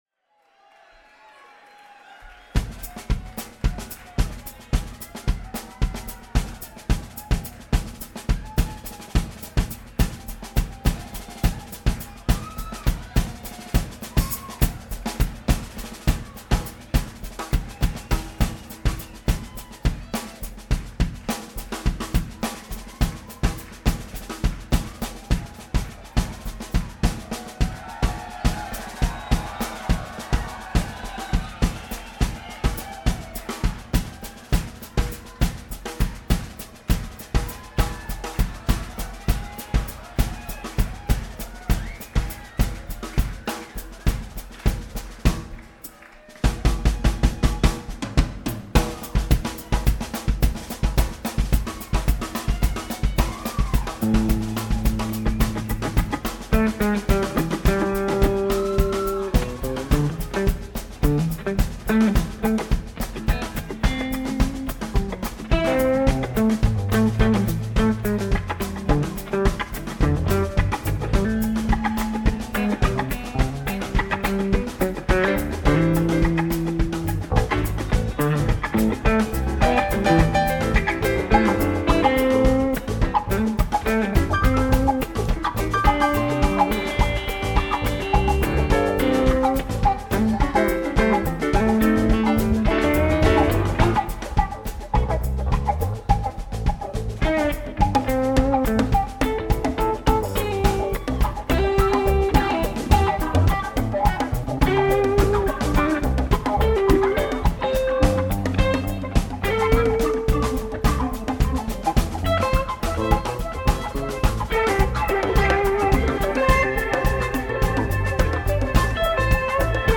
Drum Solo